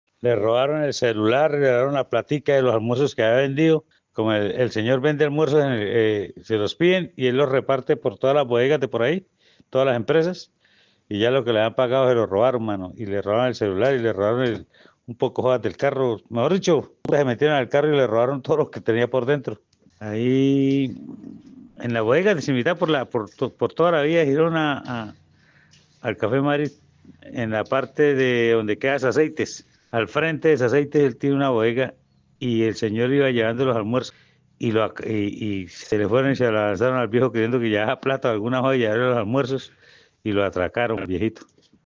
trabajador del sector